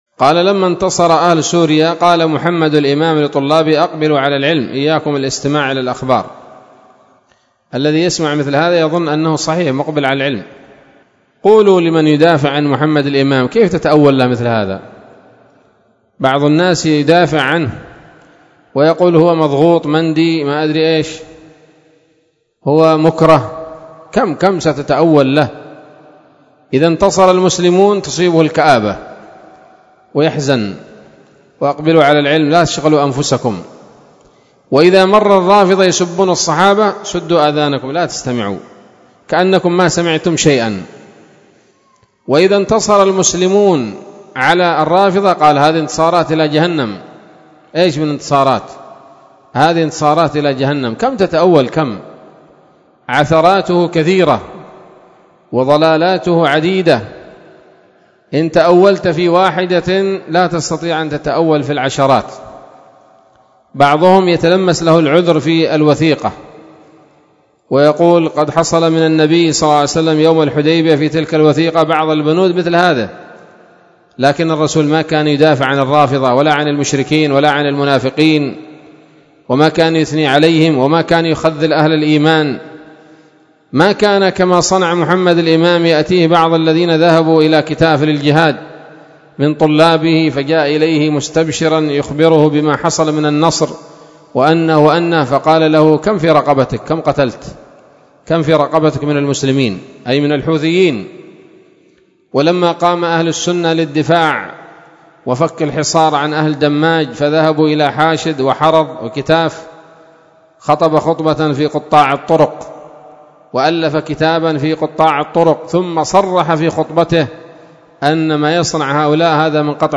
كلمة قيمة
بدار الحديث السلفية بصلاح الدين